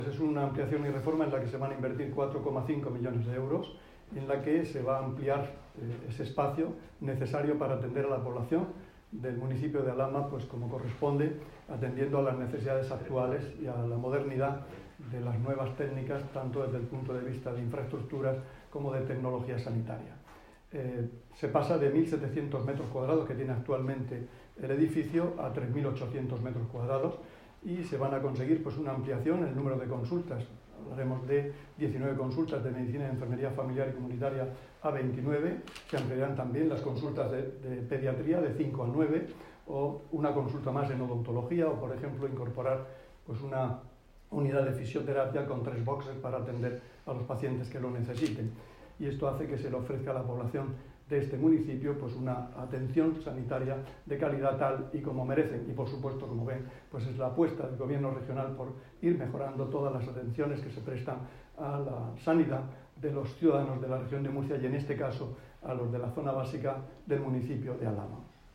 Declaraciones del consejero de Salud, Juan José Pedreño, sobre las obras del centro de salud de Alhama de Murcia [mp3].